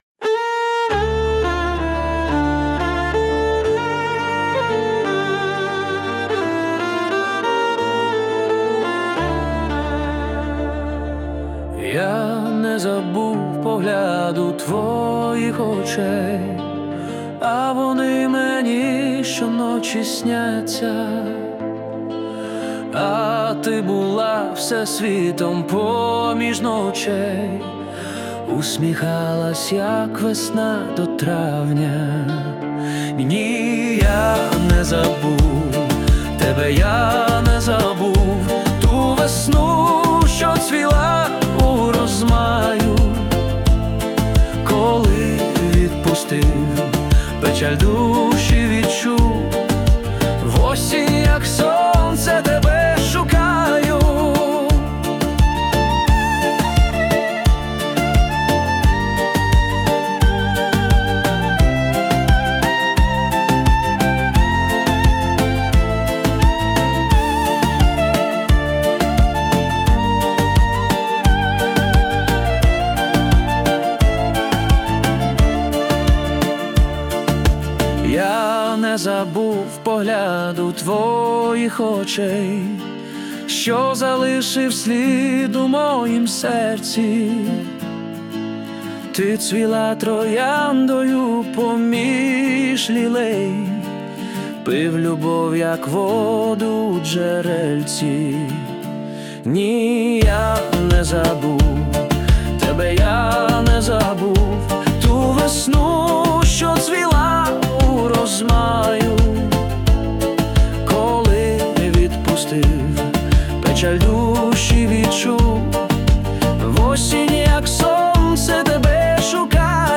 чудова пісня, мелодійна, душевна give_rose
слова чудові... музична обробка ШІ 021 cup
Дуже гарна пісня про кохання на чудові слова автора.